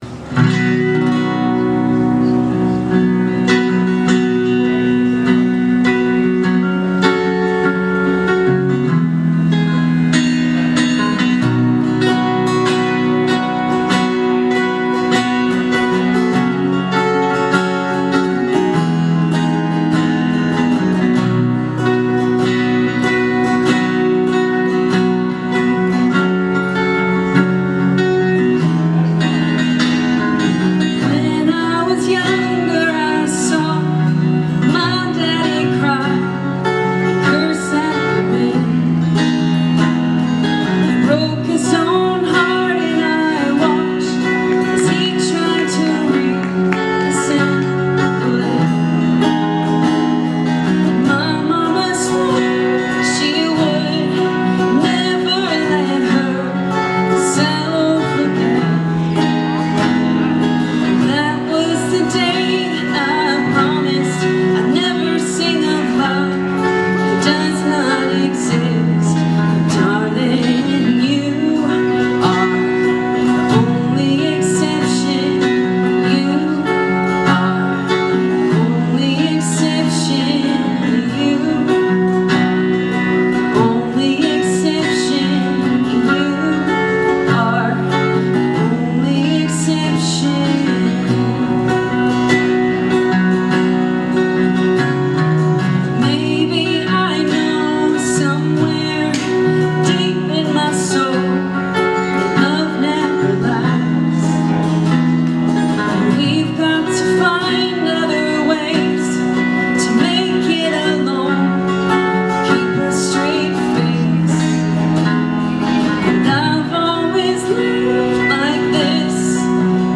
Live Anderson River Park 10/25